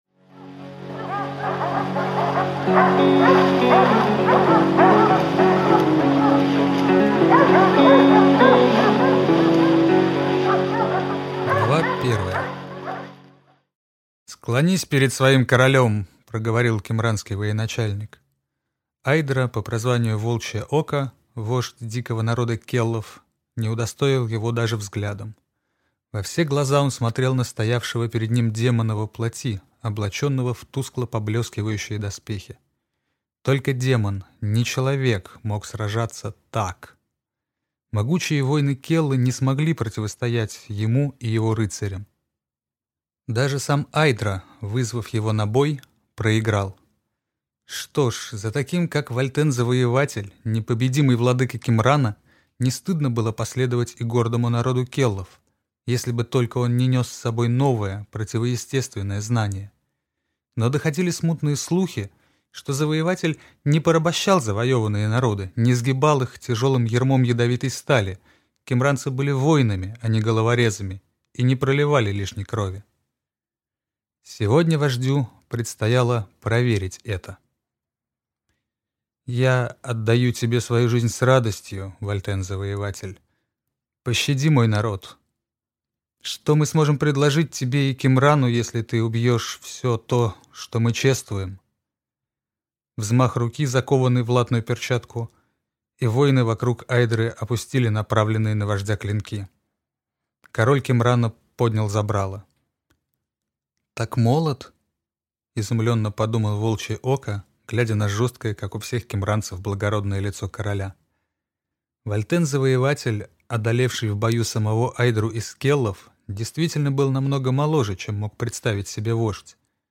Аудиокнига Зов Дикой Охоты | Библиотека аудиокниг